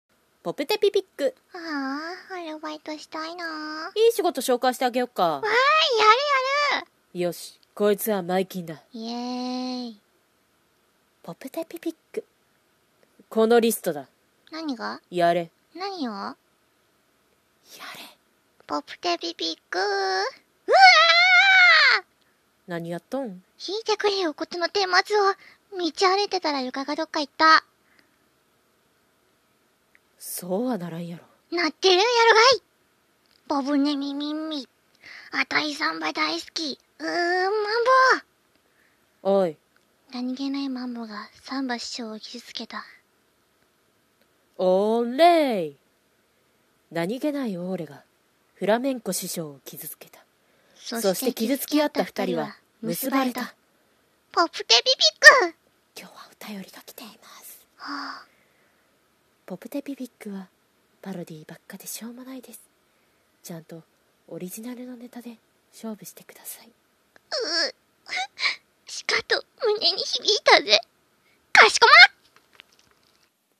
ポプテピピック 3話好きなシーン 声劇